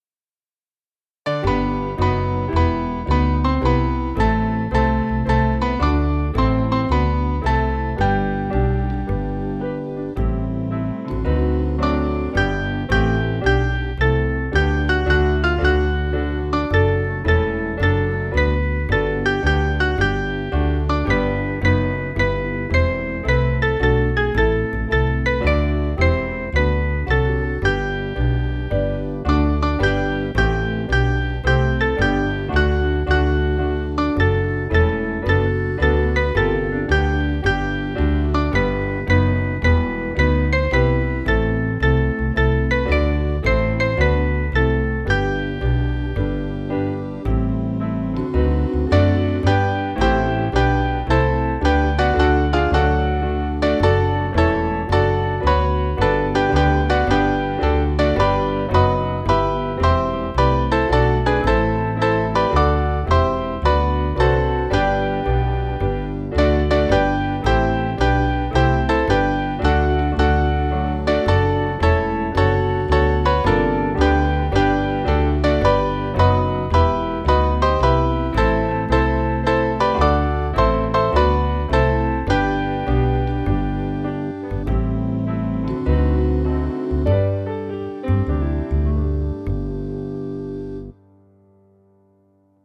Kid`s club music
Mainly Piano